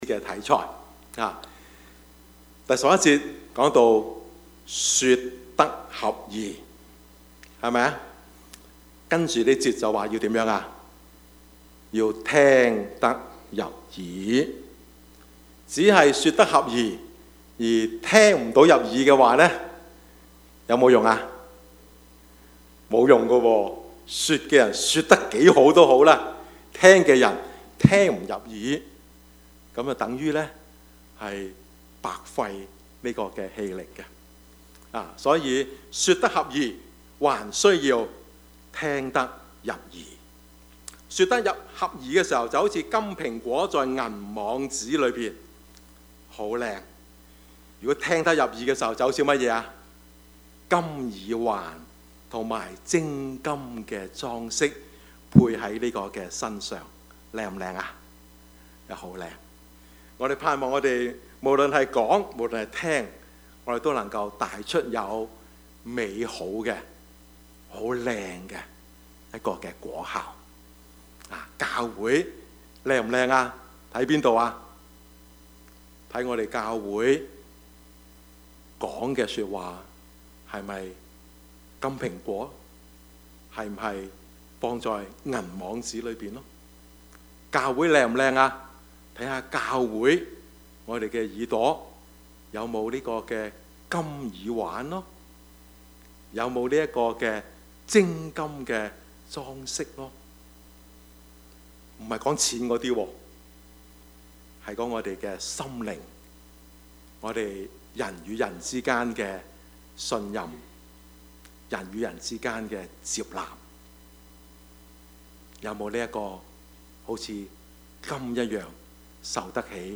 Service Type: 主日崇拜
Topics: 主日證道